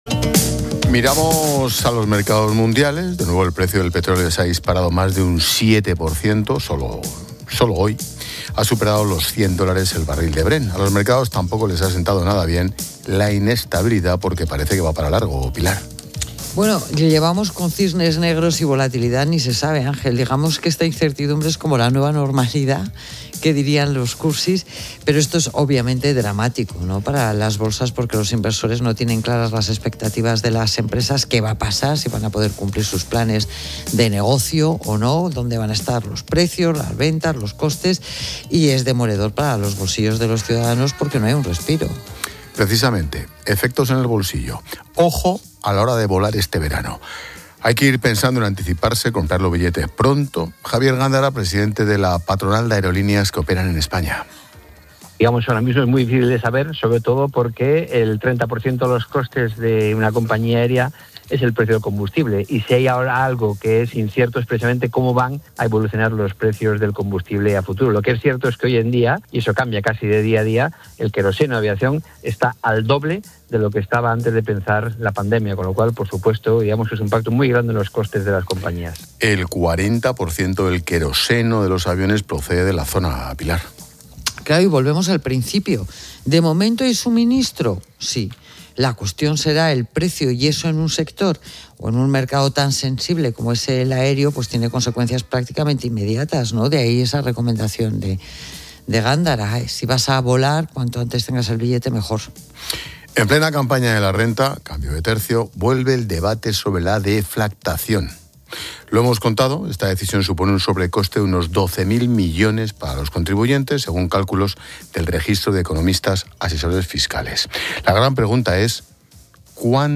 Expósito aprende en Clases de Economía de La Linterna con la experta económica y directora de Mediodía COPE, Pilar García de la Granja, sobre las consecuencias del bloqueo de Ormuz en los mercados y la aviación